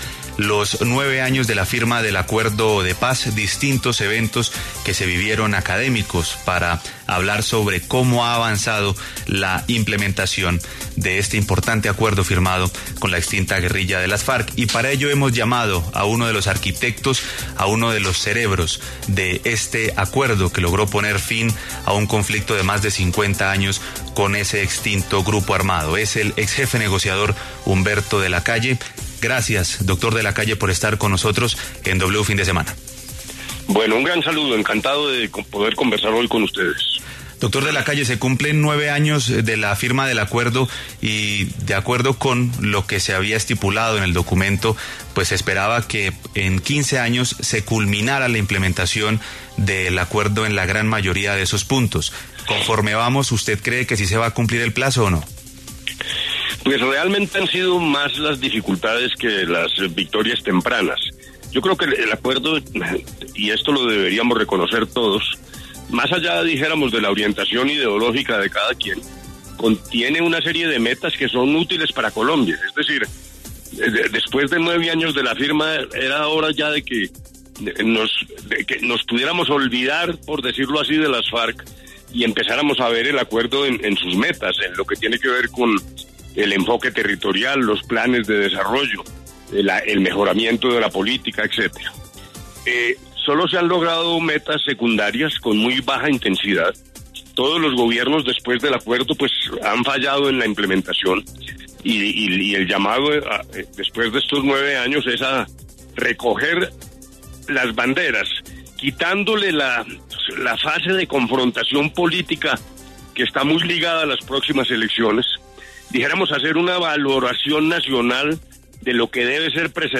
Humberto De la Calle conversó con W Fin de Semana sobre las dificultades que ha enfrentado el Acuerdo de Paz del 2016 para su implementación.